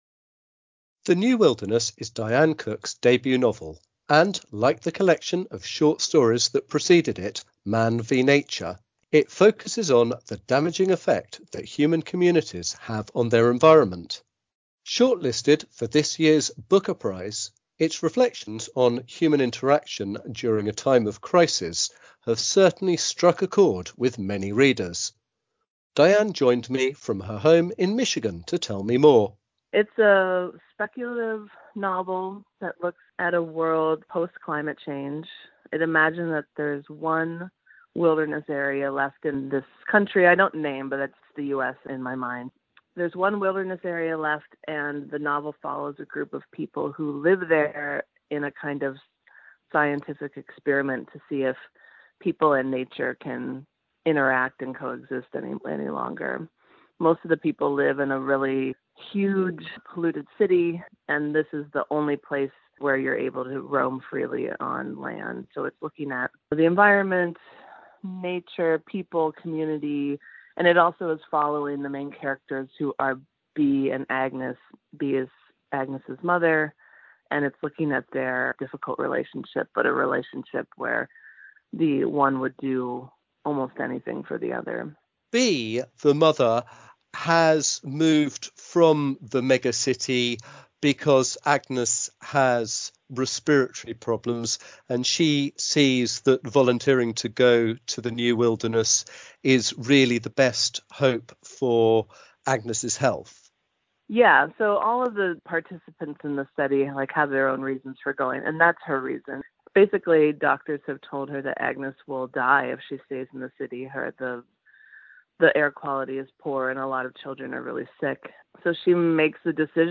Interview with Diane Cook, Booker Prize Shortlisted Author